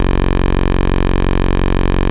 OSCAR 13 F#1.wav